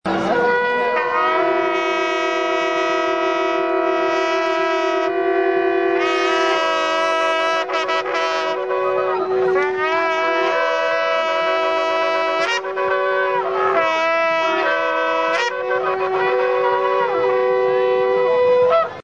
The Silver trumpet makes a sound like the more familiar brass trumpet with valves.
In this clip, recorded at the Feast of Tabernacles Jerusalem March in October 2005, the silver trumpet can be heard along with a Shofar and one man blowing two shofarot at the same time.
TRUMPETS.MP3